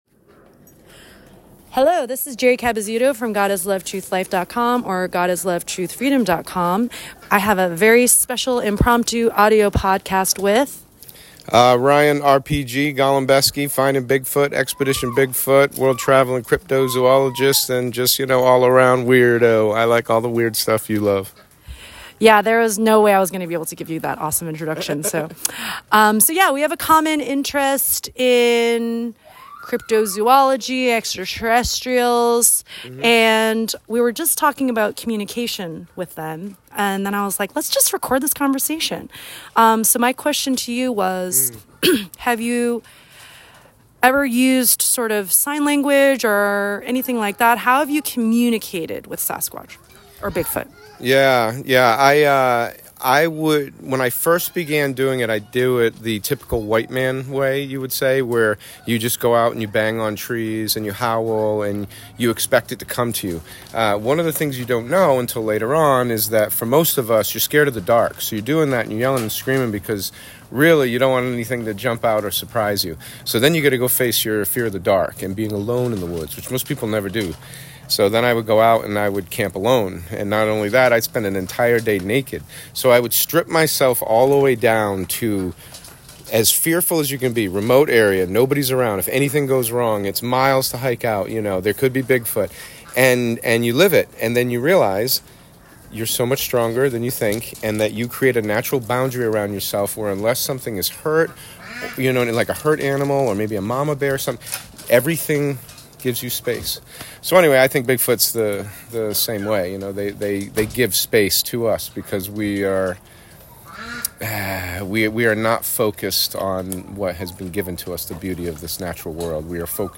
I recently audio-recorded a conversation